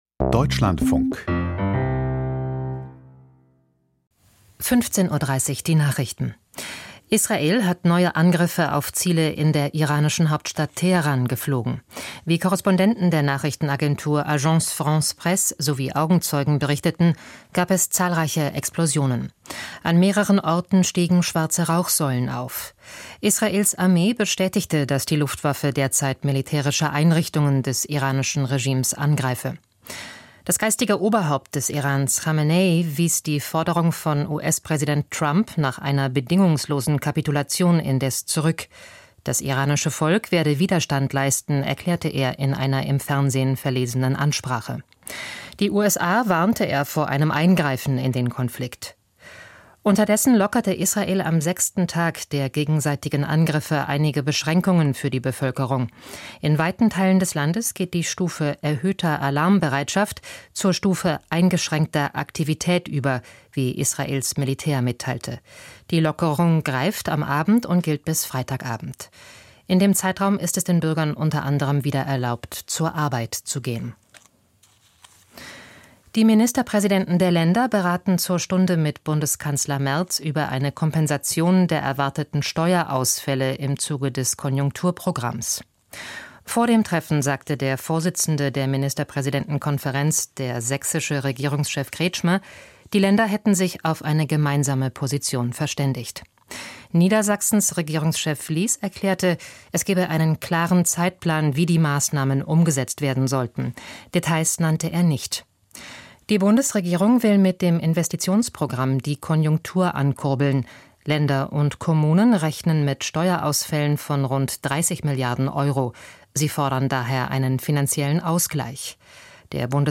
Die Nachrichten vom 18.06.2025, 15:30 Uhr